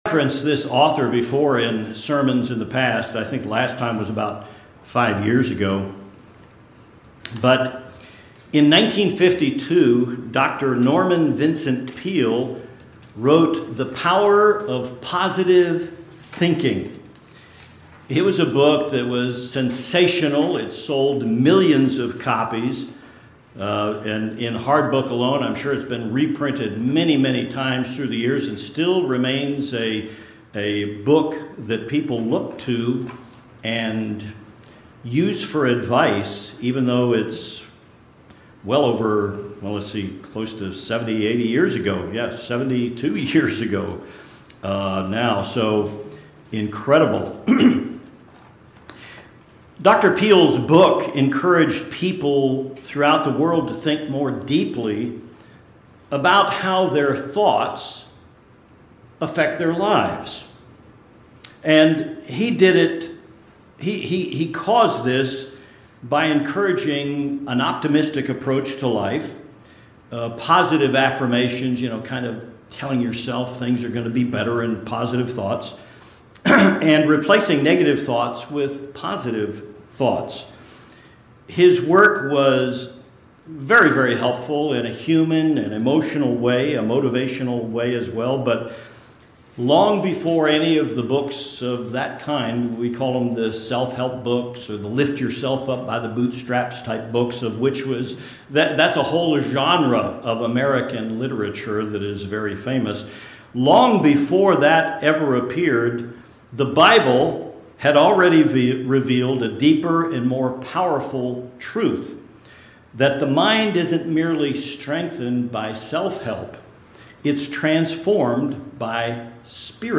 Neuroscience has proven that positive thinking is effective in "re-wiring" your brain. This sermon details how the Bible encourages us to use the Holy Spirit to change our thought process.